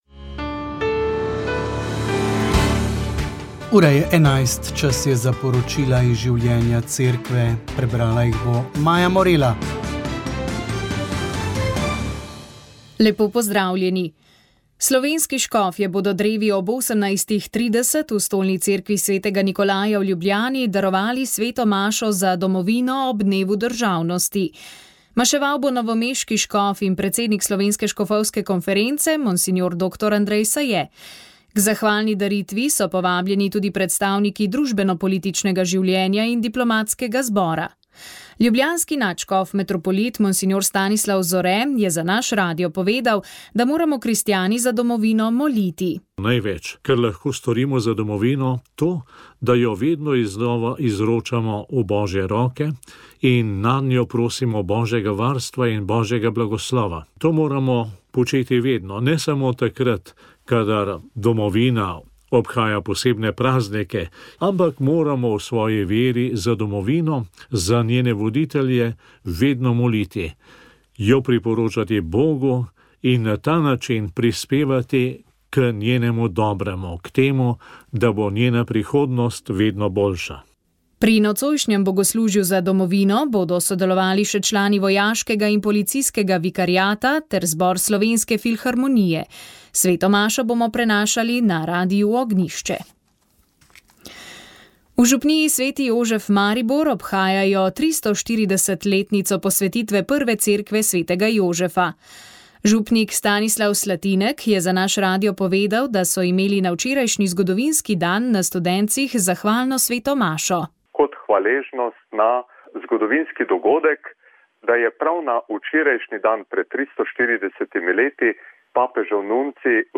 Danes bomo prebrali komentar